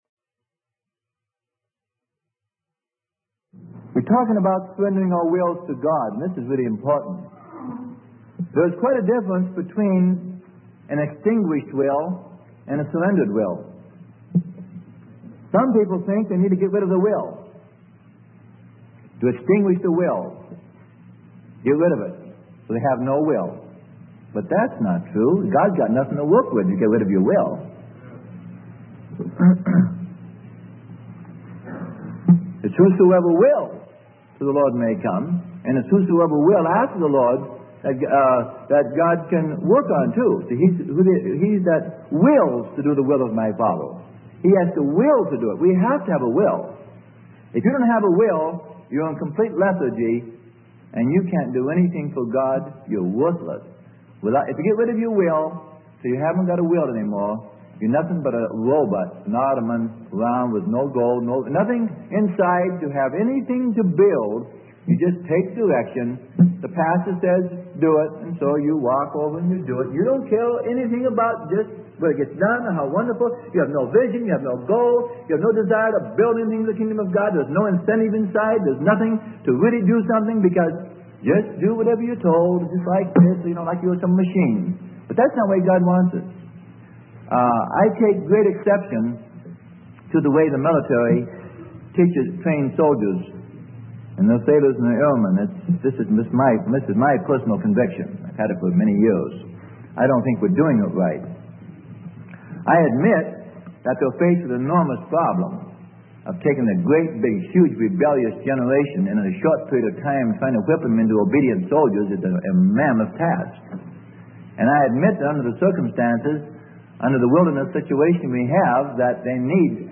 Sermon: How to be Led of God - Part 11 - Freely Given Online Library